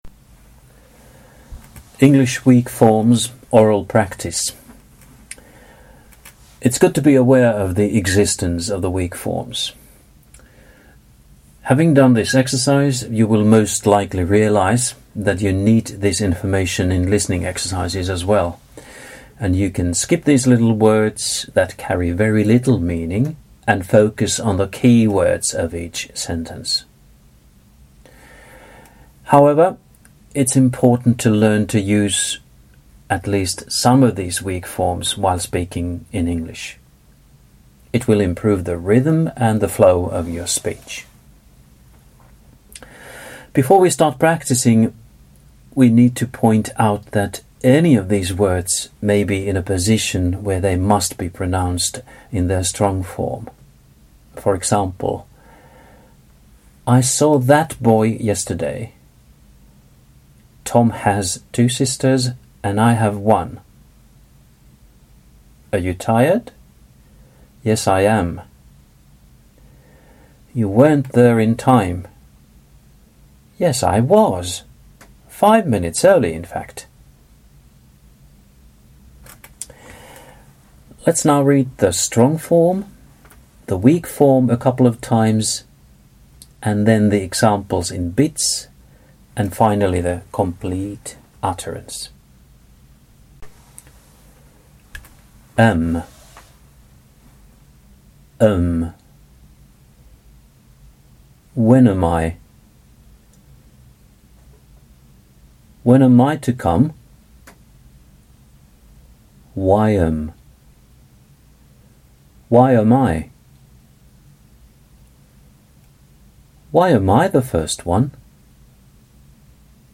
2_english-weak-forms-oral-practice.mp3